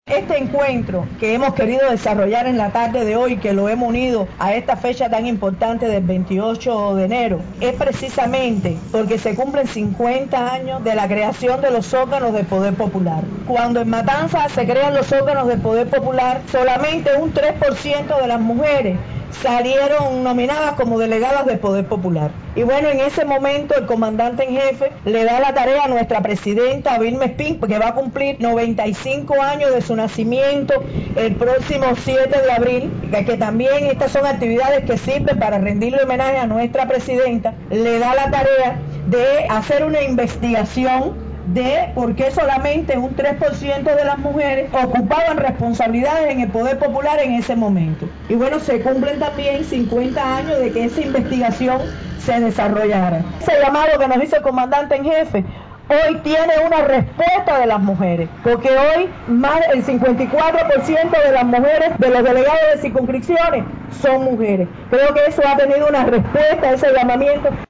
En el otrora colegio electoral donde Fidel ejerció el derecho al voto en las elecciones experimentales de 1974, realizaron el acto de recibimiento a la organización de las jóvenes.
Declaraciones-de-la-Secretaria-General-de-la-FMC.mp3